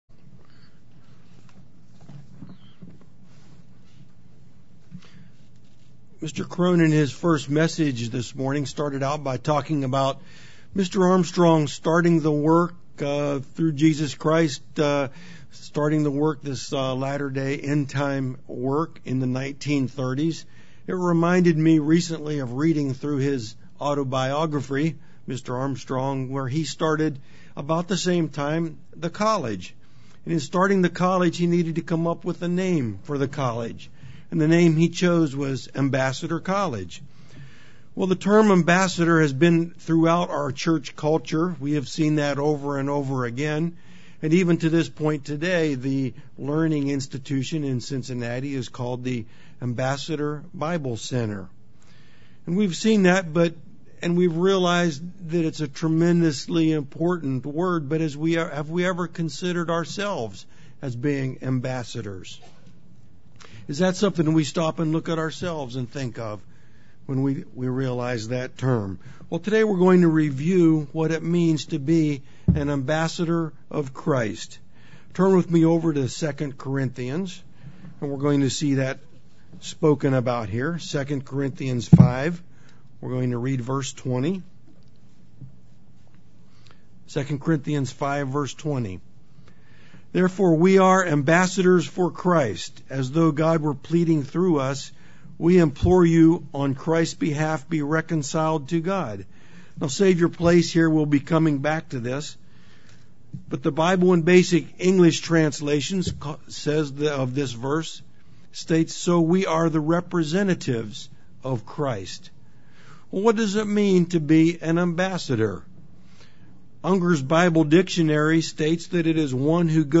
UCG Sermon Studying the bible?
Given in Tampa, FL